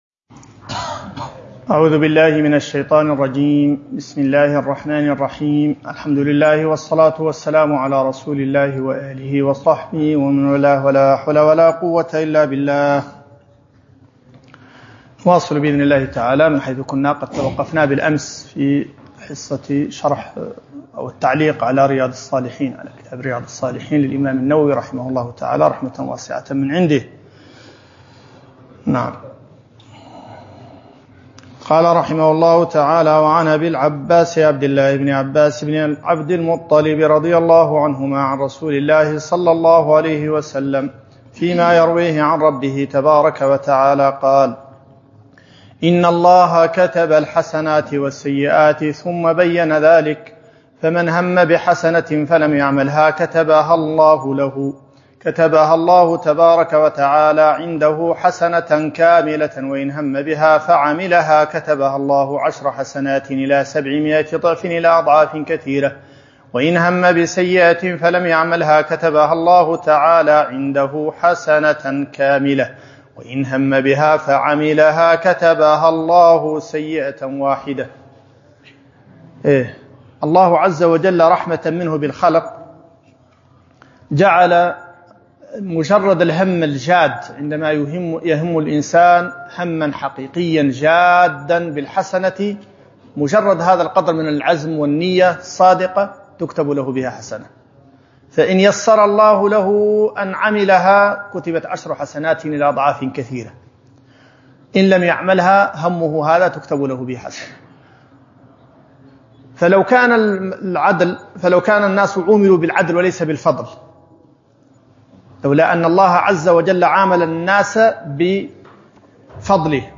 شرح كتاب رياض الصالحين الدرس 02